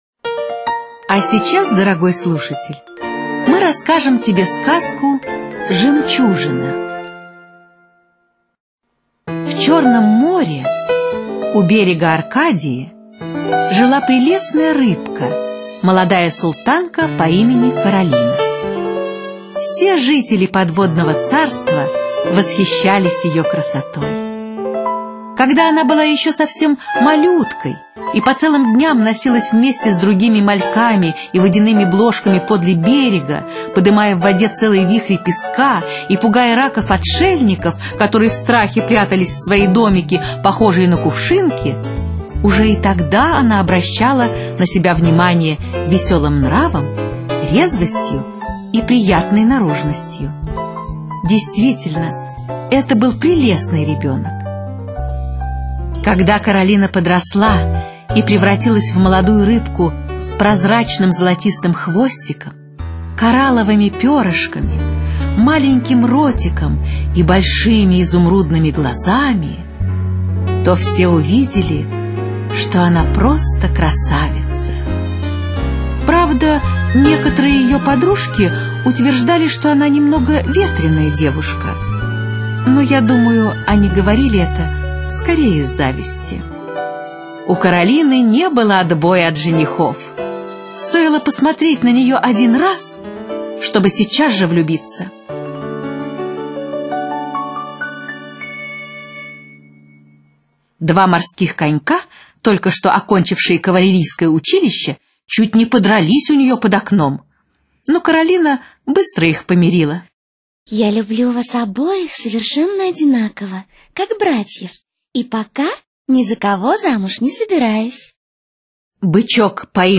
Жемчужина - аудиосказка Валентина Катаева - слушать онлайн